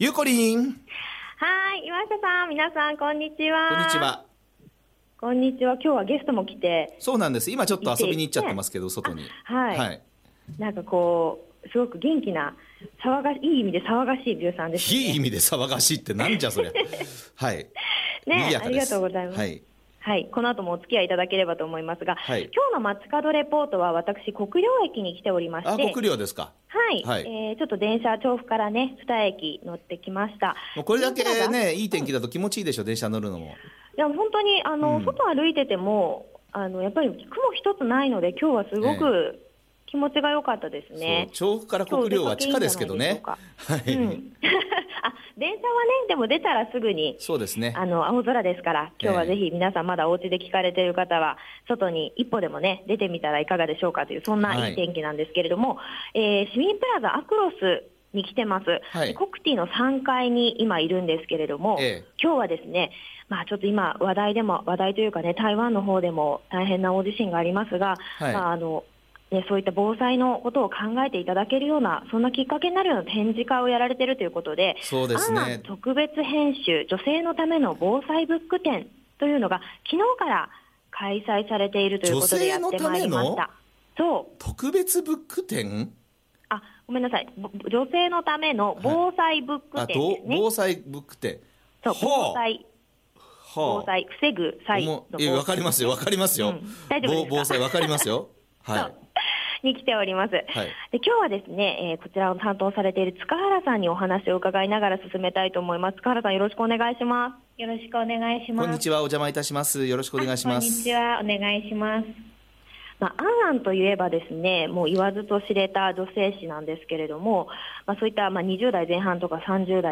さて！2月最初の街角レポートは、国領駅市民プラザあくろすの3階で、昨日から開催中の 「an・an特別編集 女性のための防災BOOK展」にお邪魔してきましたよ★